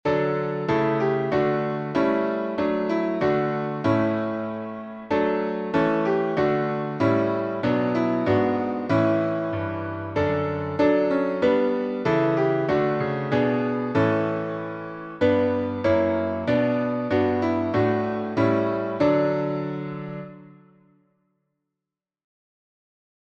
Key signature: D major (2 sharps) Time signature: 4/4